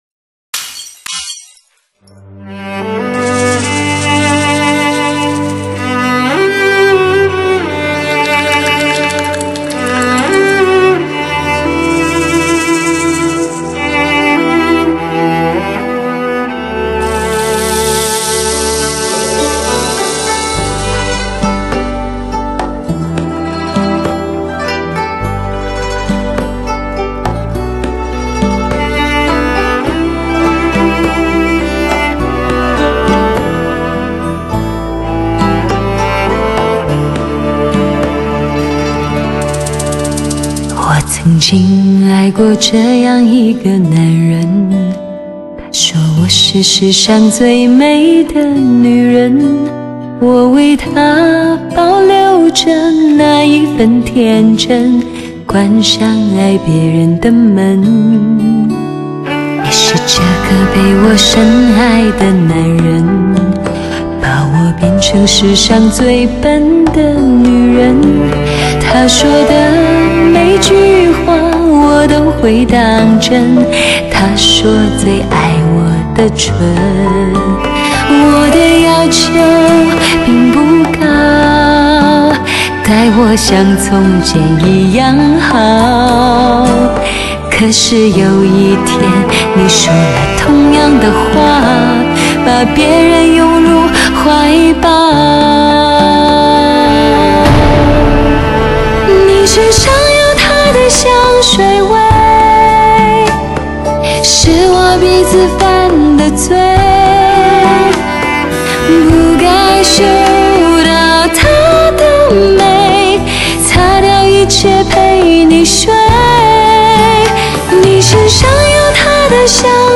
保证了音色的清晰度和更宽阔的音域动态范围。